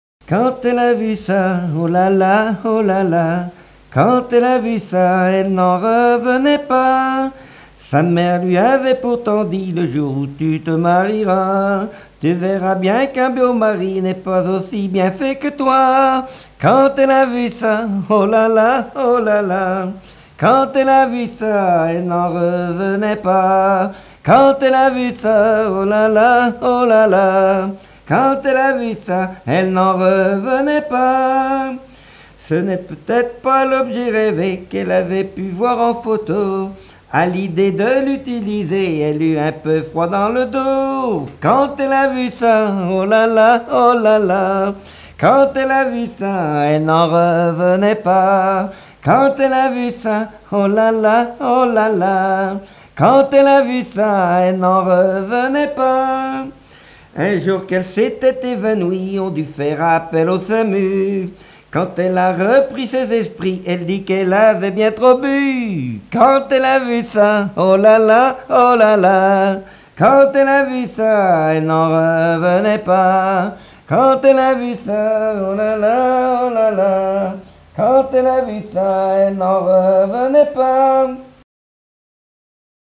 Genre strophique
Chansons populaires et traditionnelles
Pièce musicale inédite